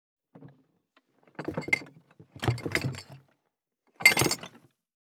182,荷物運び,段ボール箱の中身,部署移動,ザザッ,ドタドタ,バリバリ,カチャン,ギシギシ,ゴン,ドカン,ズルズル,タン,パタン,
効果音荷物運び